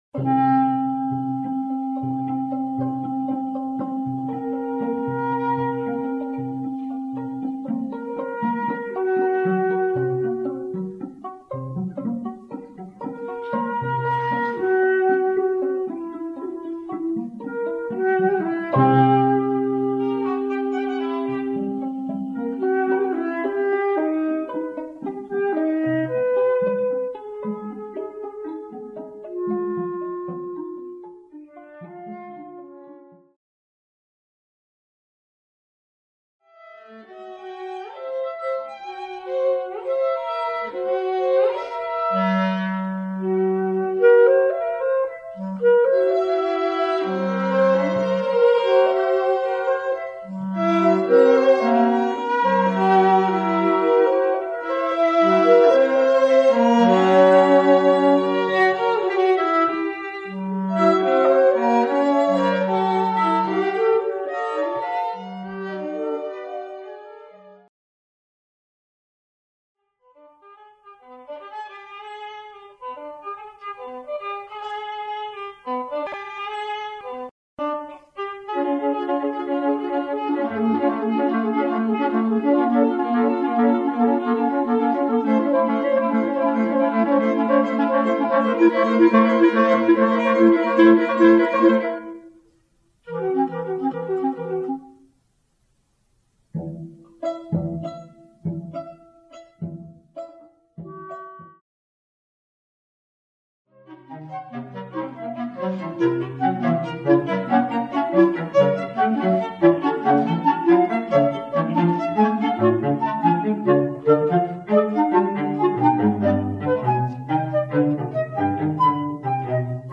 (1994), for flute, clarinet, violin, and cello. 10 minutes.